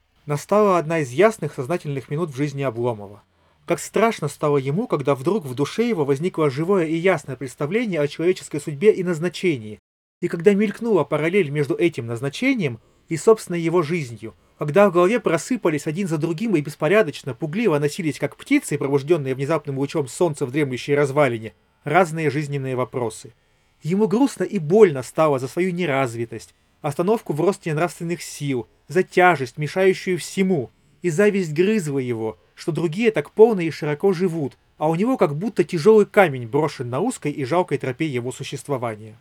Второй записан на расположенный примерно на том же расстоянии профессиональный рекордер с заведомо высоким качеством записи и приводится для сравнения.
Запись на профессиональный рекордер
2-Recorder.mp3